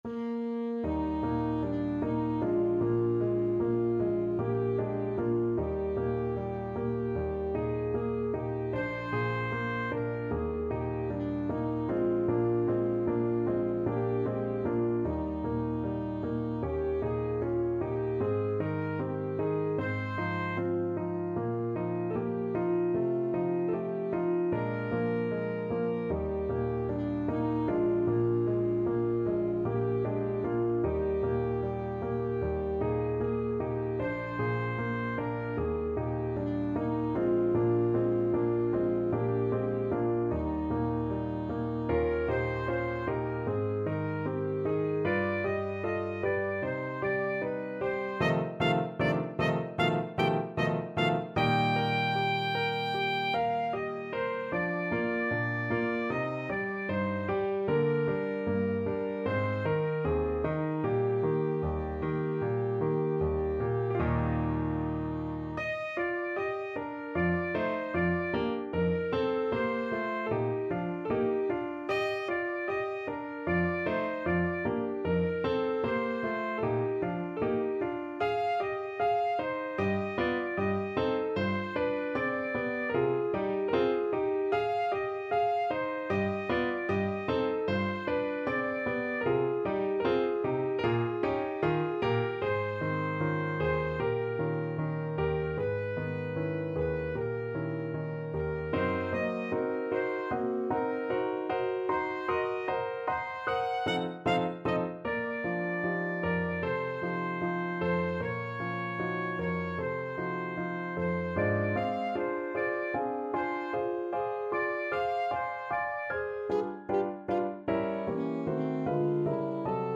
Classical Chopin, Frédéric Op.10 No.3 (Tristesse) Alto Saxophone version
Alto Saxophone
Eb major (Sounding Pitch) C major (Alto Saxophone in Eb) (View more Eb major Music for Saxophone )
Lento ma non troppo = c.76
2/4 (View more 2/4 Music)
Classical (View more Classical Saxophone Music)
chopin_etude_10_3_ASAX.mp3